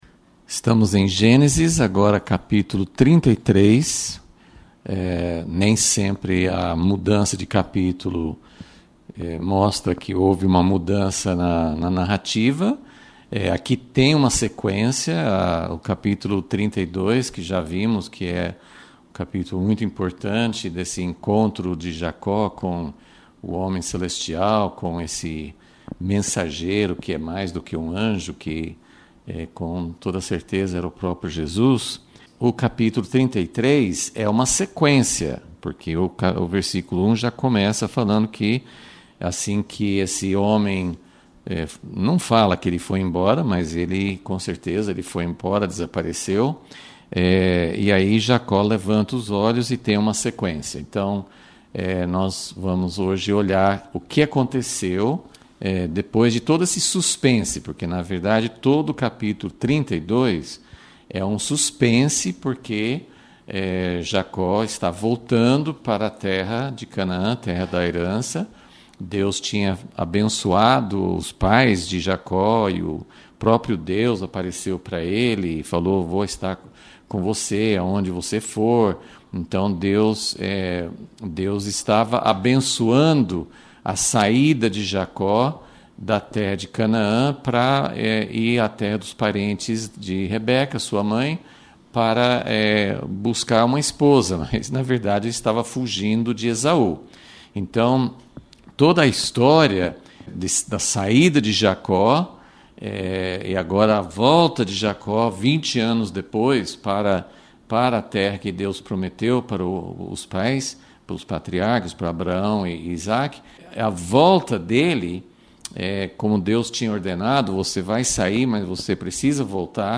Aula 96 - Gênesis - O final da história de Esaú e Jacó — Impacto Publicações